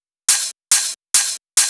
VTDS2 Song Kit 11 Pitched Freaking Open.wav